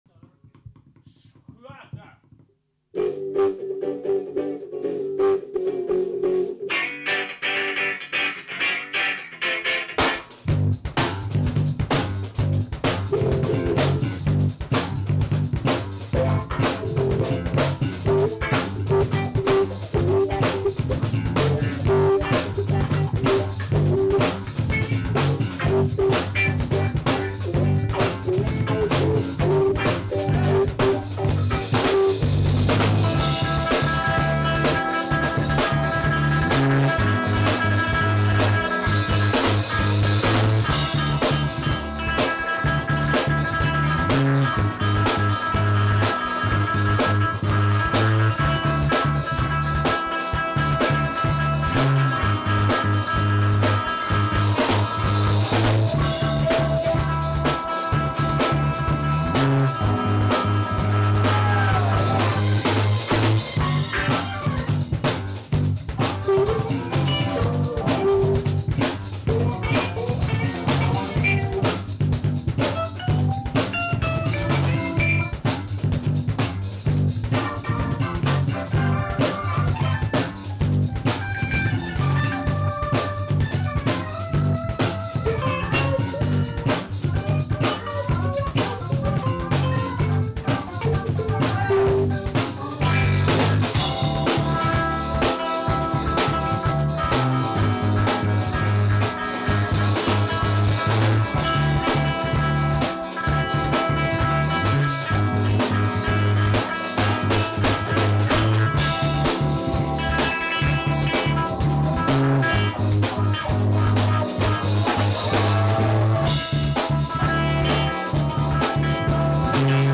funk.m4a